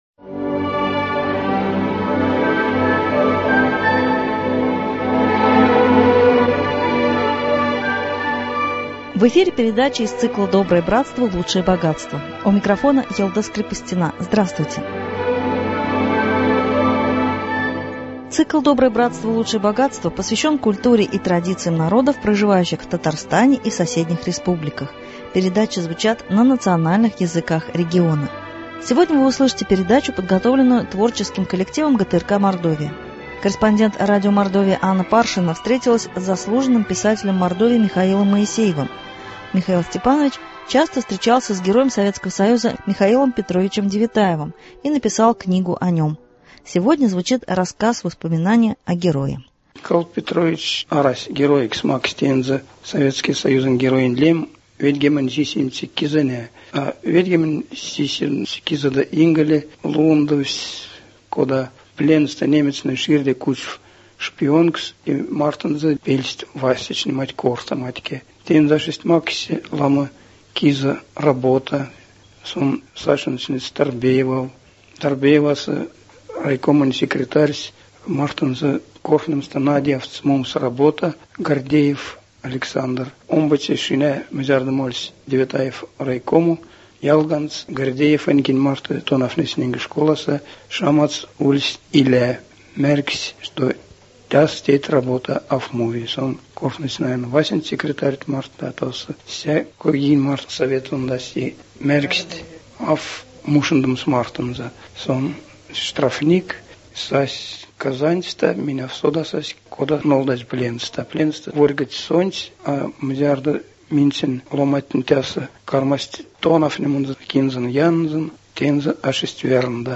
И сегодня звучит рассказ-воспоминание о Герое.